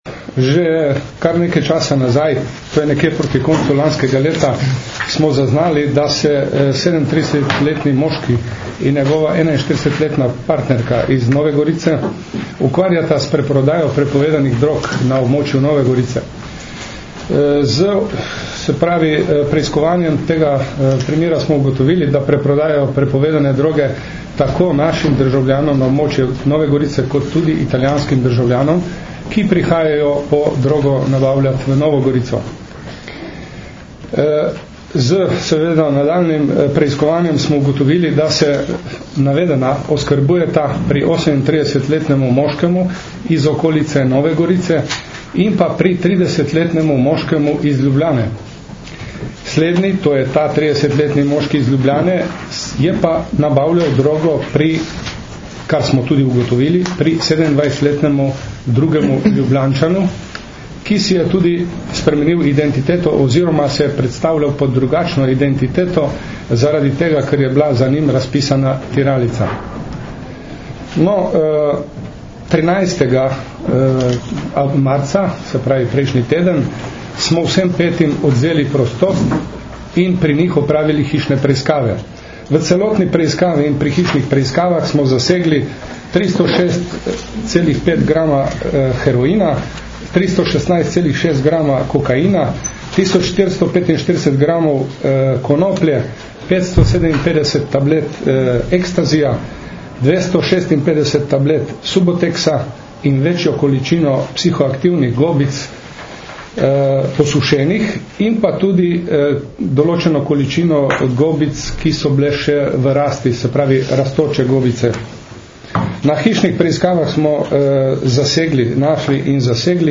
Na današnji novinarski konferenci na Policijski upravi Nova Gorica sta predstavnika Generalne policijske uprave in PU Nova Gorica podrobneje opisala rezultate nedavno uspešno zaključene preiskave kriminalne združbe, ki se je na območju Nove Gorice in okolice ukvarjala s preprodajo prepovedanih drog.
Izjava